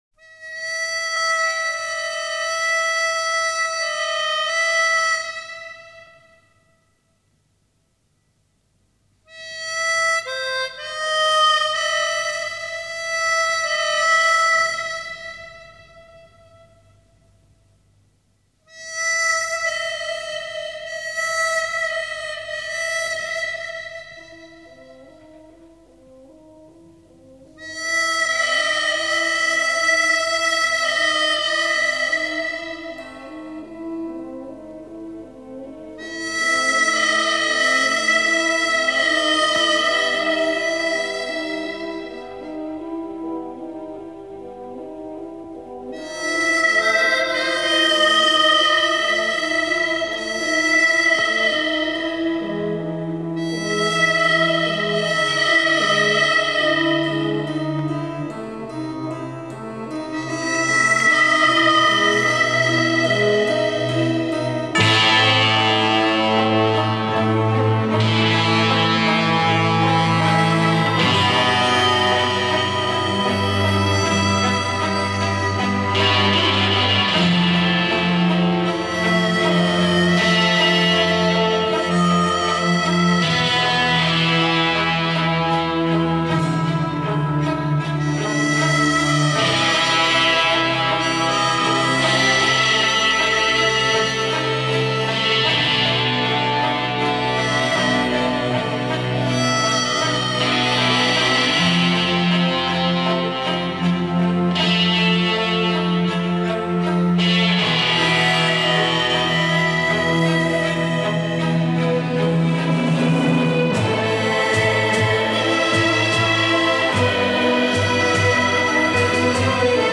__________________________________ The man with the harmonica __________________________________
harmonica.mp3